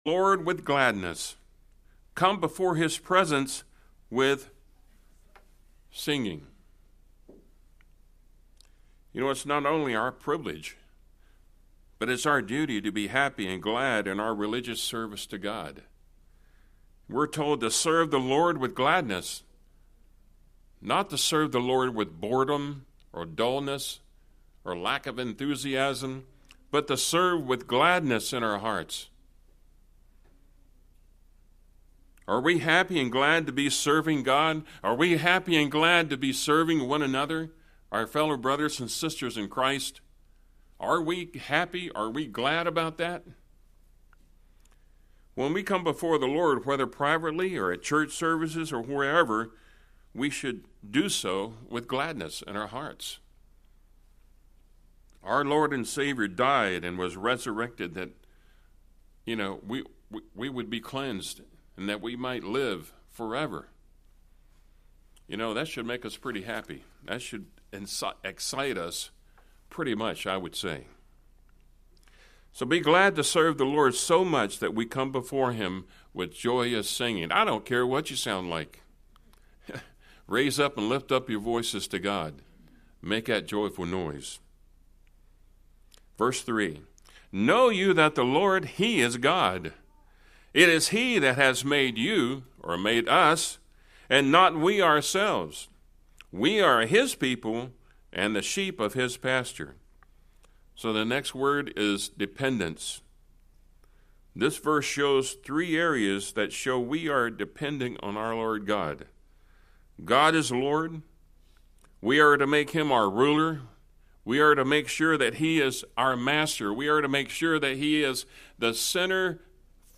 With Thanksgiving only several weeks away, join us for this important sermon that will enlighten you on how to be more Thankful.